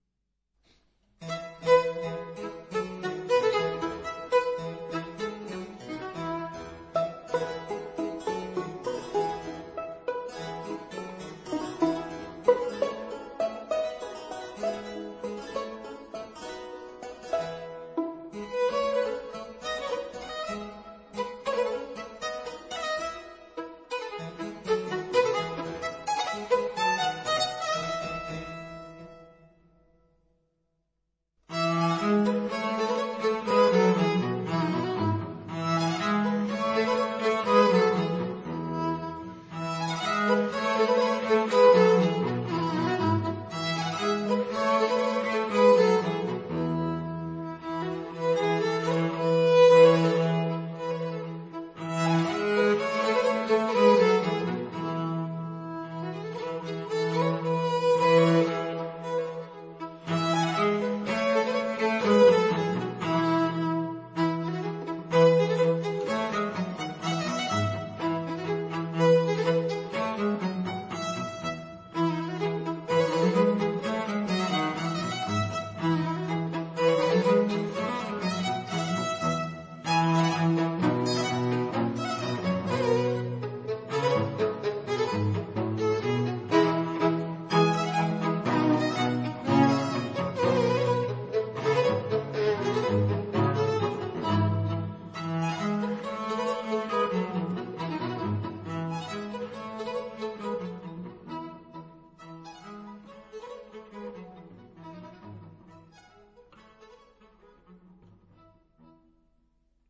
這張專輯裡，雖是以小提琴、大提琴、大魯特琴、大鍵琴為主的四重奏，
但音樂主角很明顯還是在小提琴。